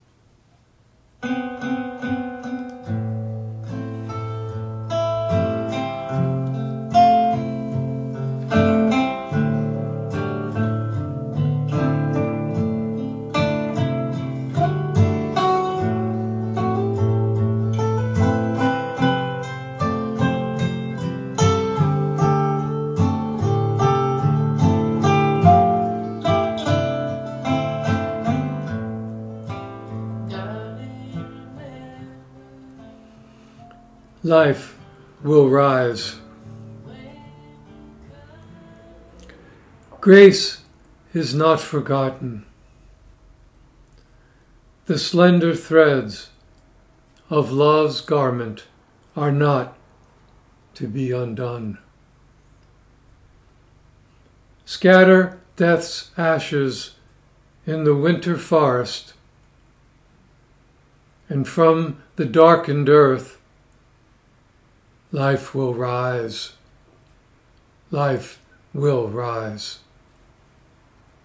Reading of “Life Will Rise” with intro music by Gillian Welch and David Rawlings